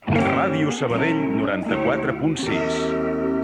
Indicatiu emissora.